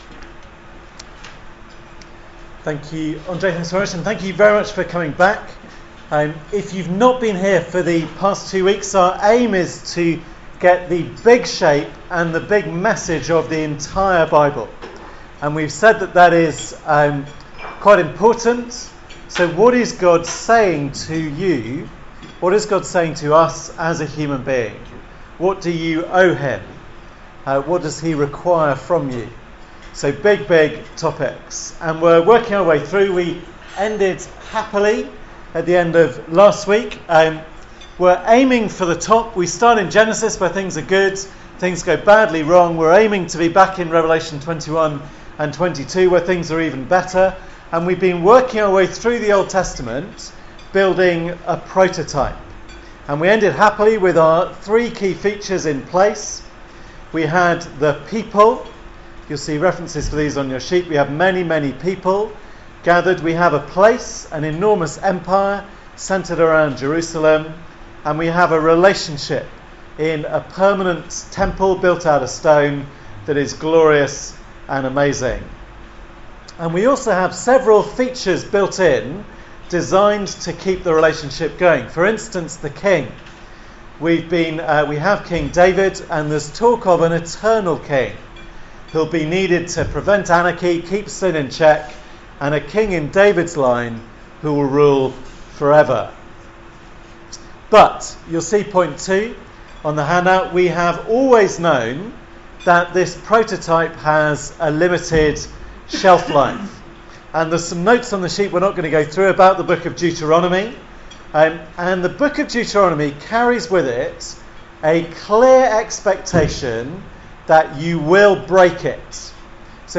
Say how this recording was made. Part of the Archived Talks series, given at a Wednesday meeting